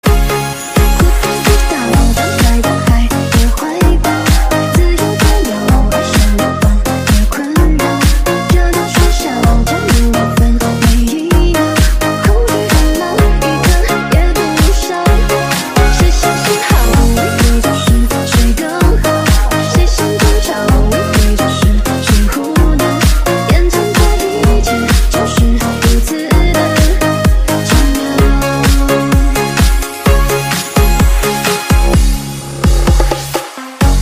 Nhạc Chuông Nhạc Hoa